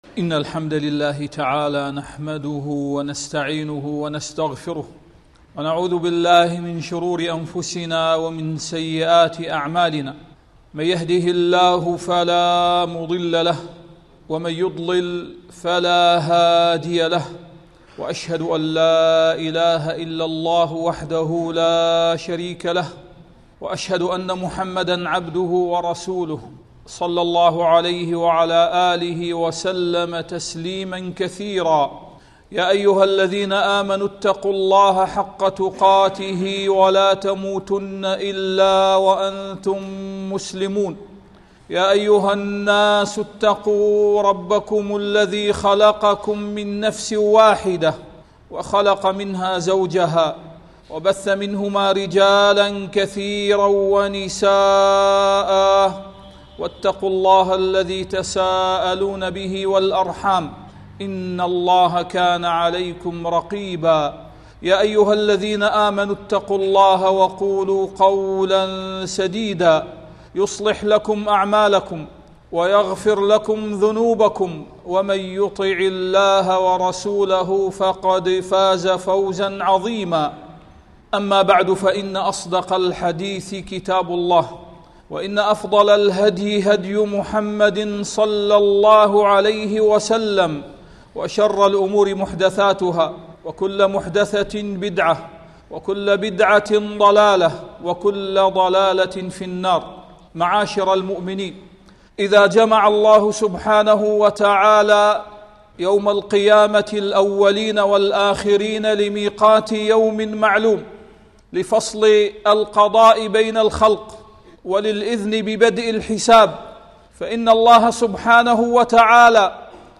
العمارة الحقيقية للآخرة بالعمل، فهي دار لا تصلح للمفاليس!. التصنيف: خطب الجمعة